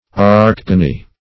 Search Result for " archegony" : The Collaborative International Dictionary of English v.0.48: Archegony \Ar*cheg"o*ny\, n. [See Archegonium .]